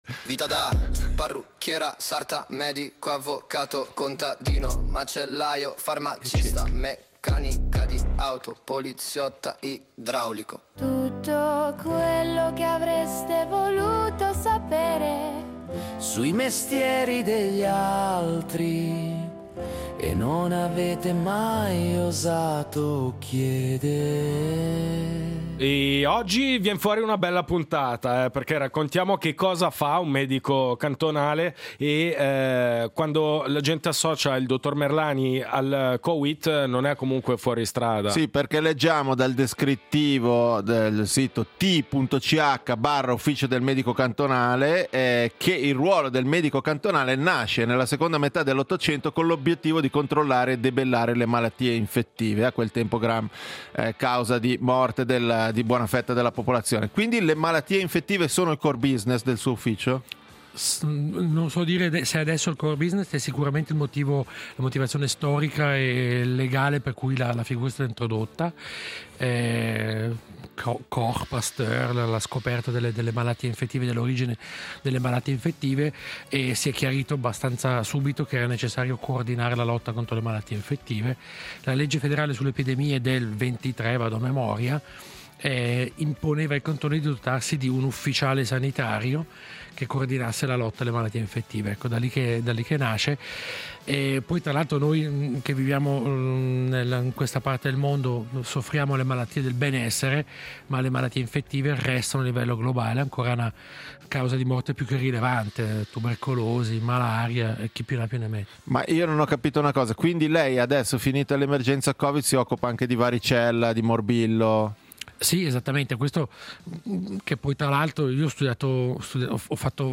Ospite in studio: Giorgio Merlani